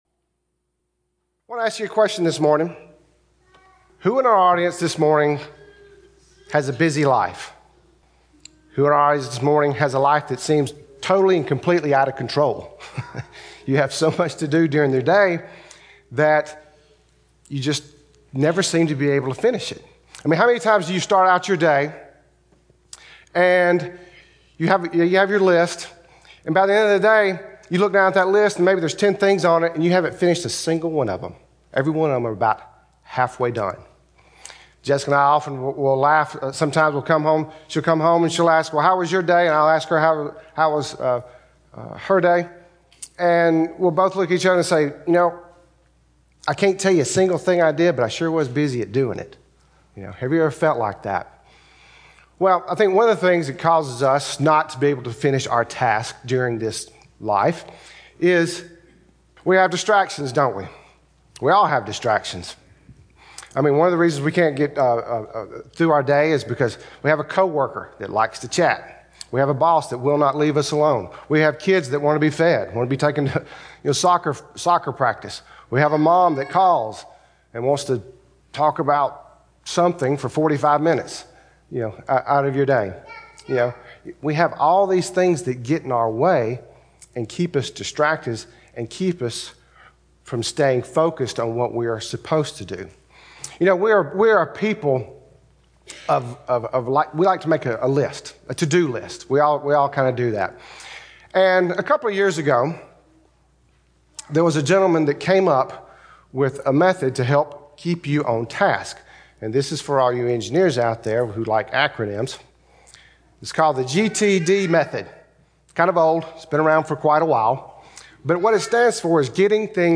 Service: Sun AM Type: Sermon